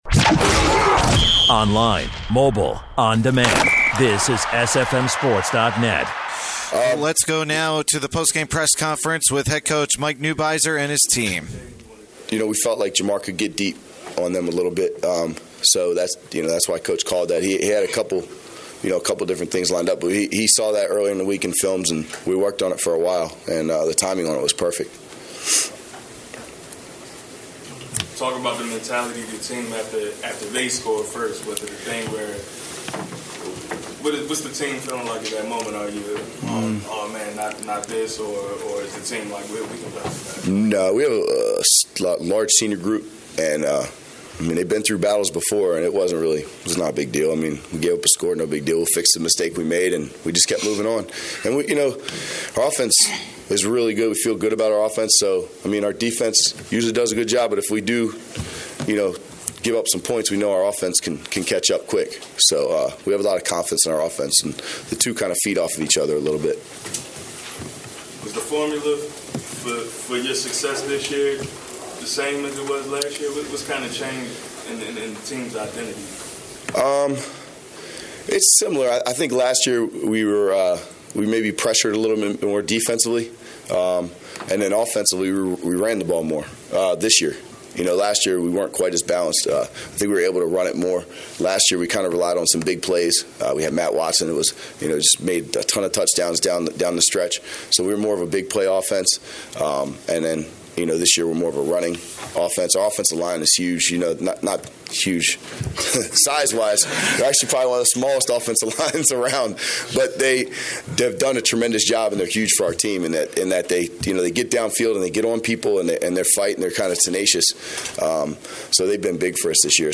4A: Northwest Jaguars Post Game Press Conference